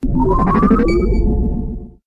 starcraft-probe-warp-notify_26212.mp3